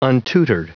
Prononciation du mot untutored en anglais (fichier audio)